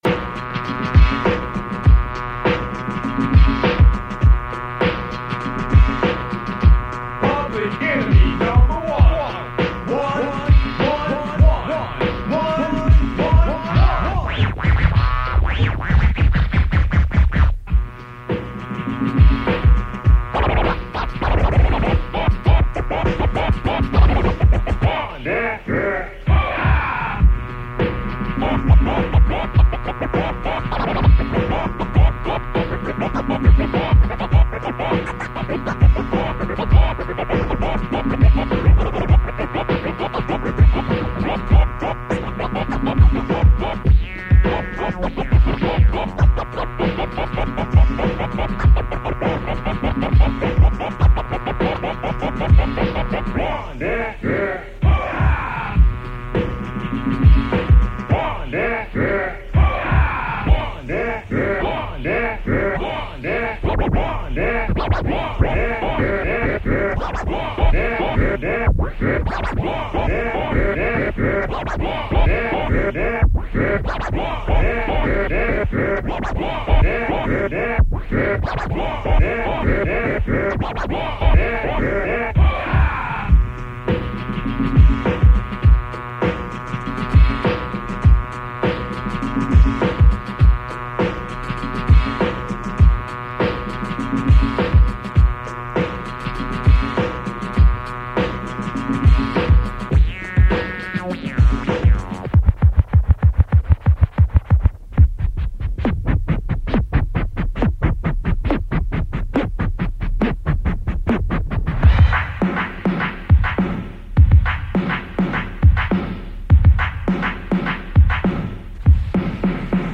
it features plenty of turntable antics